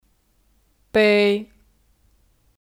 杯 (Bēi 杯)